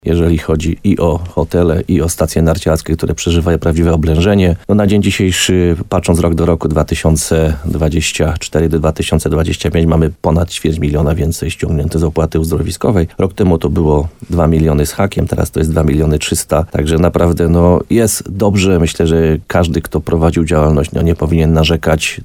– Rozpoczęła się też na dobre wiosenna rekreacja, bo pogoda była na tyle dobra, że wiele osób decydowało się na wypady w góry na dwóch kółkach – mówił burmistrz Krynicy-Zdroju, Piotr Ryba w programie Słowo za Słowo na antenie RDN Nowy Sącz.